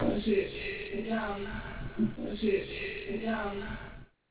Il ya huit samples, qui constituent la banque de sons avec laquelle j'ai composé les quatres exemples de mixage disponibles sur cette page .